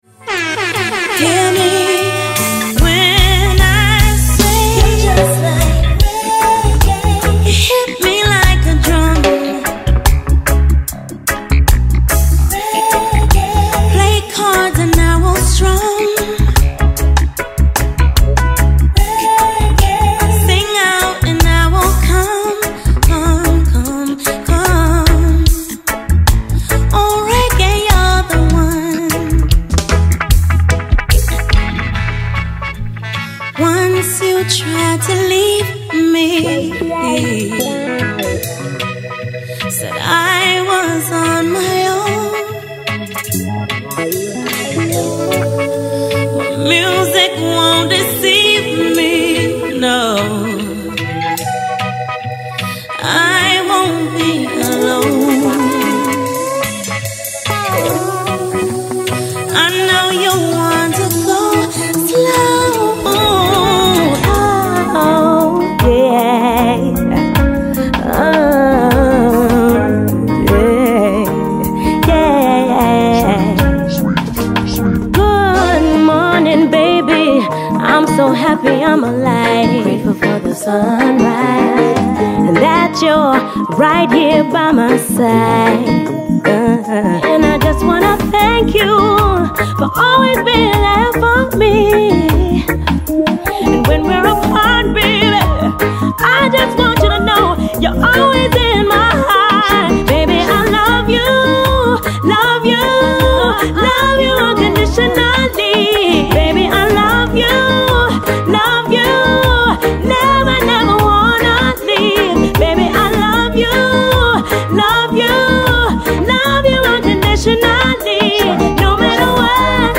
10-minute mix